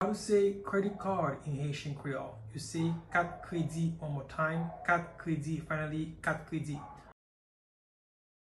“Kat kredi” Pronunciation in Haitian Creole by a native Haitian can be heard in the audio here or in the video below:
Credit-card-in-Haitian-Creole-Kat-kredi-pronunciation-by-a-Haitian-teacher.mp3